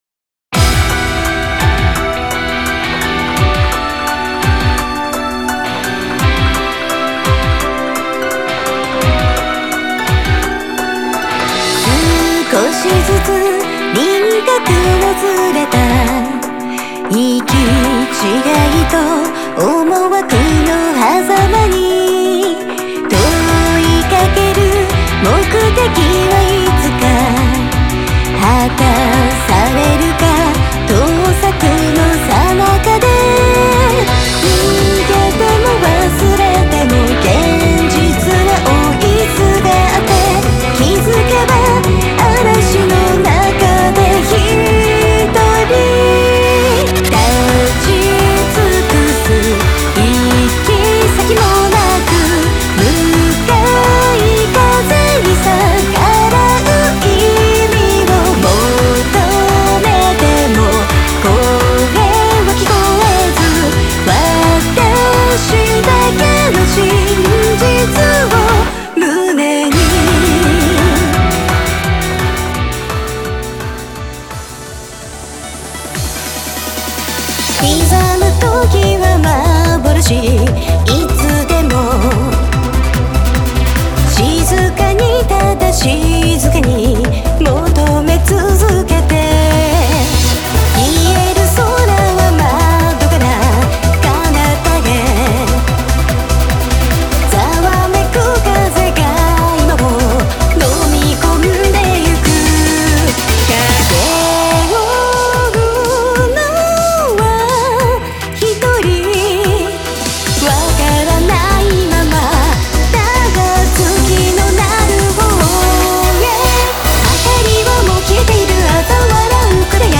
（全10曲　Vocal:5 / Inst:5）